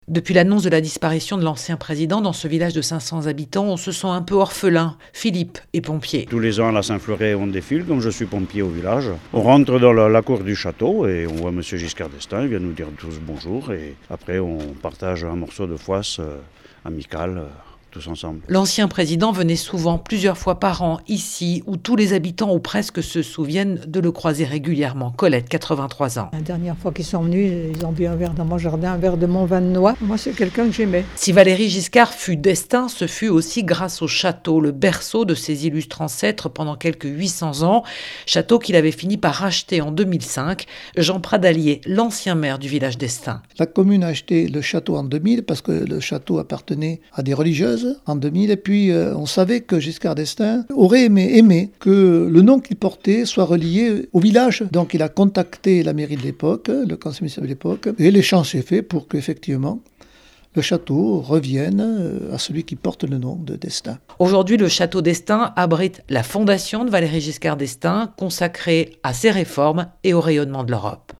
Reportage à Estaing